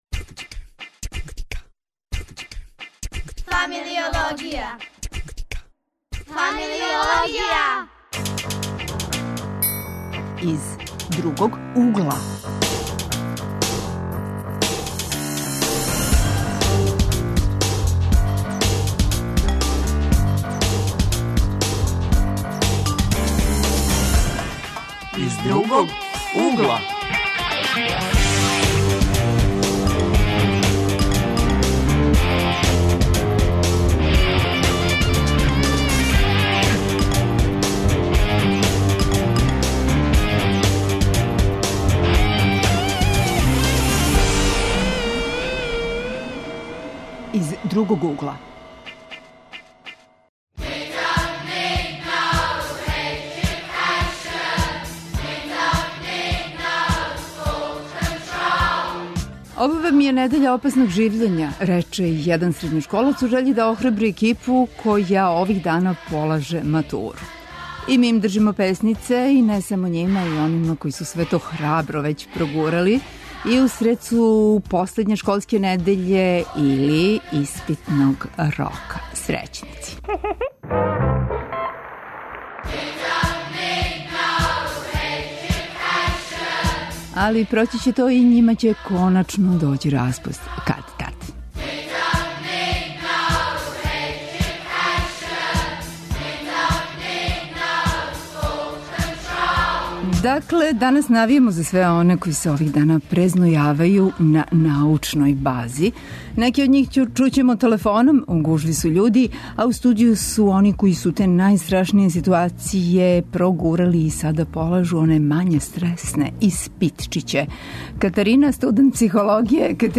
Млади гости у студију ће нам помоћи да утврдимо да ли су сви испити од великог значаја и одређују ли даљи ток живота младог човека?